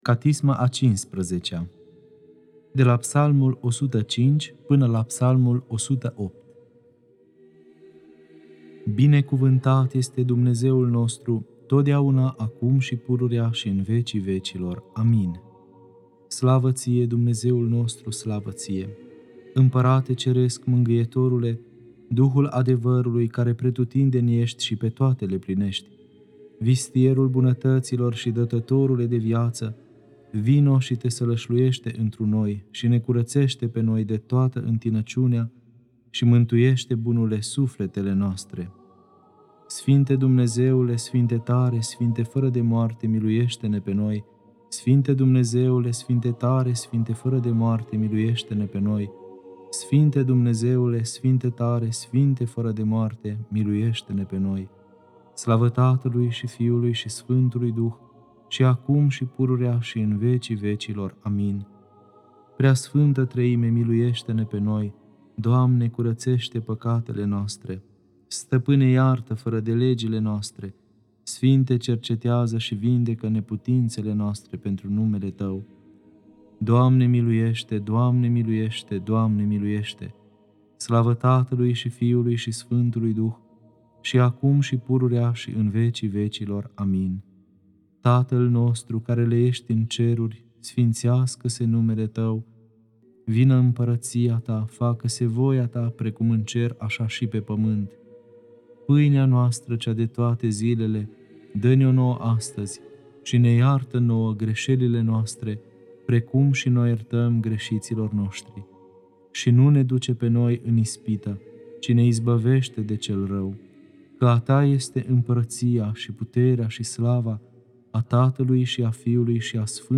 Catisma a XV-a (Psalmii 105-108) Lectura